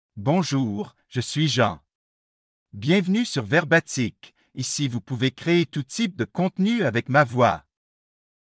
MaleFrench (Canada)
Jean is a male AI voice for French (Canada).
Voice sample
Male
Jean delivers clear pronunciation with authentic Canada French intonation, making your content sound professionally produced.